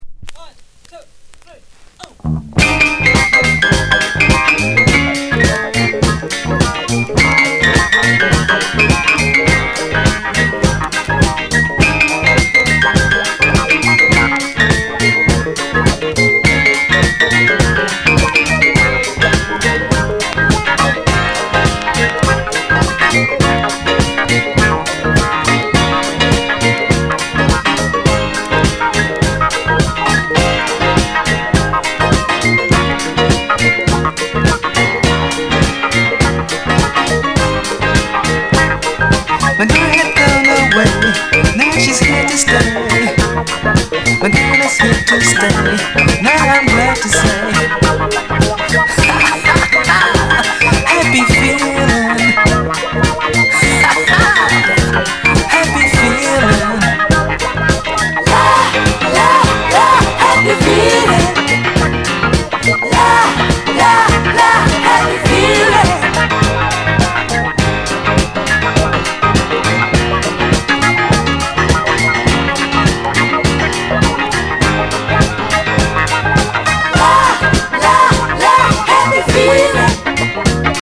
Genre: Soul